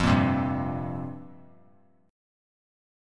Fsus2sus4 chord